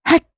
Xerah-Vox_Jump_jp.wav